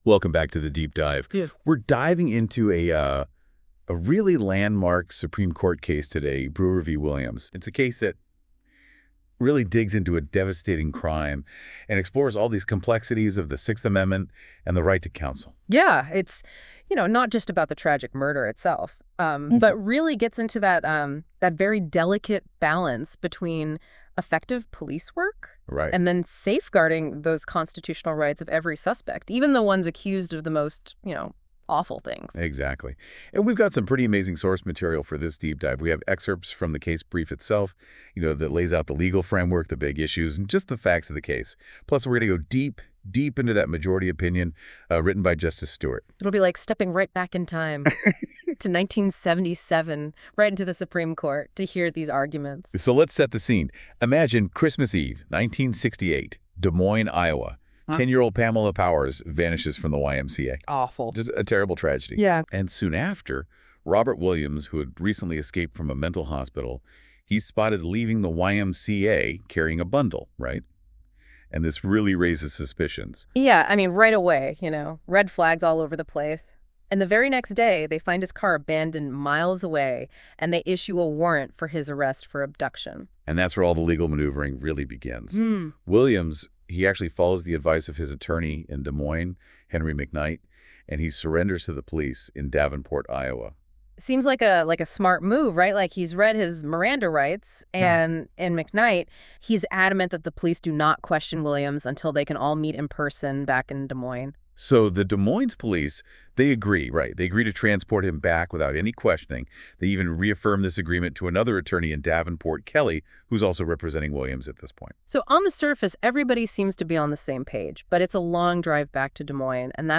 Listen to an audio breakdown of Brewer v. Williams.